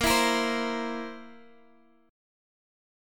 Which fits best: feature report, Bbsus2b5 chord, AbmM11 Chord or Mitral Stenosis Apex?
Bbsus2b5 chord